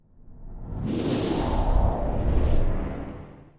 vortex.mp3